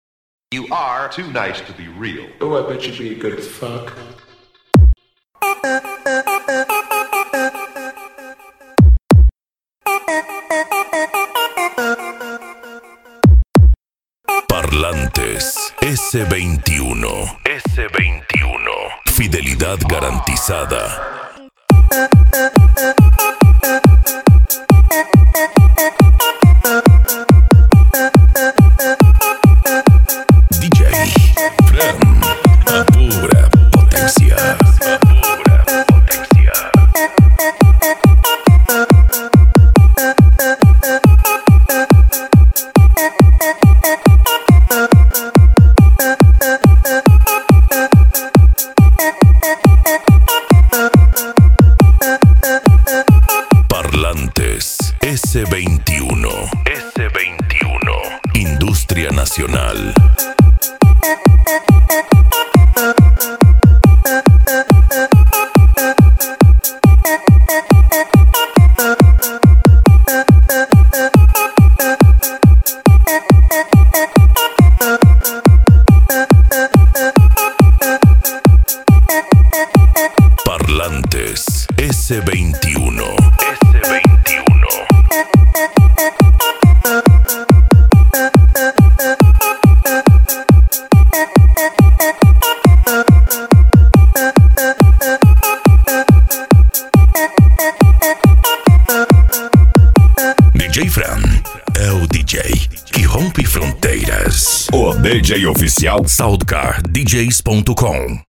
Eletronica
PANCADÃO
Psy Trance
Remix